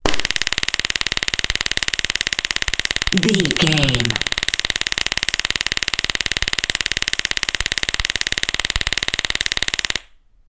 Police Taser Gun with pop and arching spark.
Police Taser
Sound Effects
shock